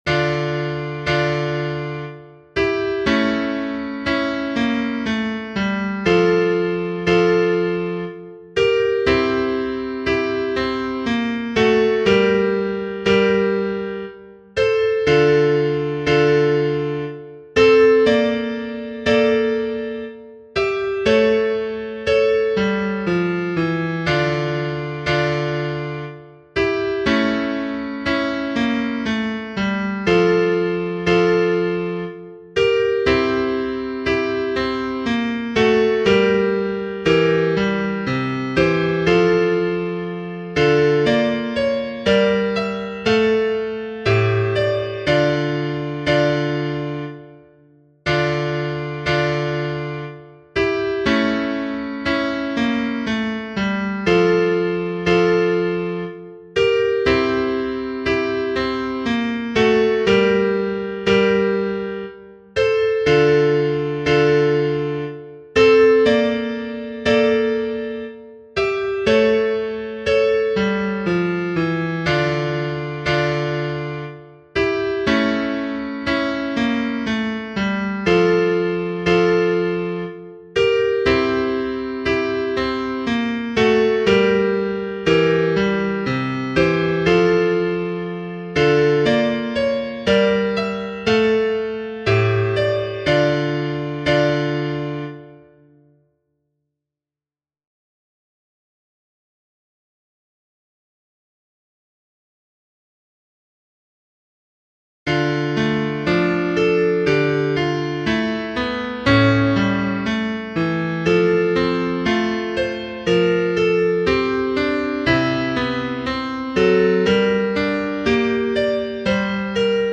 SARABANDE_tutti.mp3